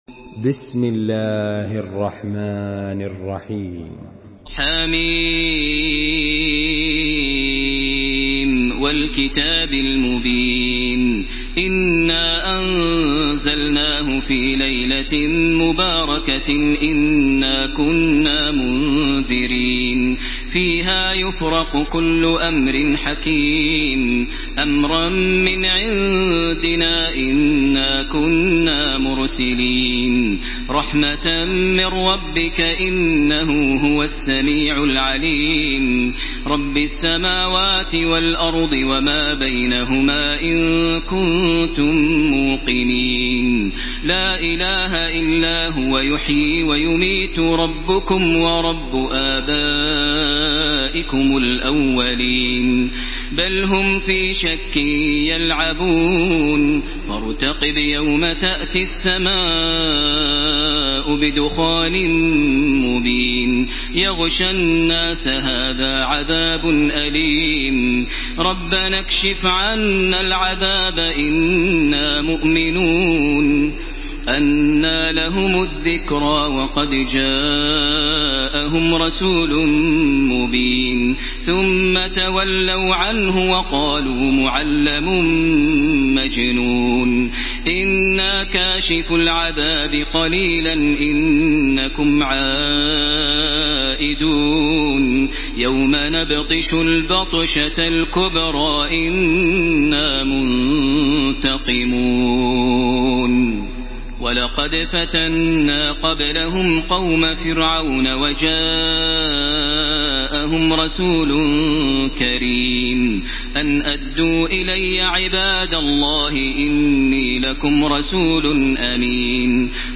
Surah Ad-Dukhan MP3 in the Voice of Makkah Taraweeh 1434 in Hafs Narration
Murattal Hafs An Asim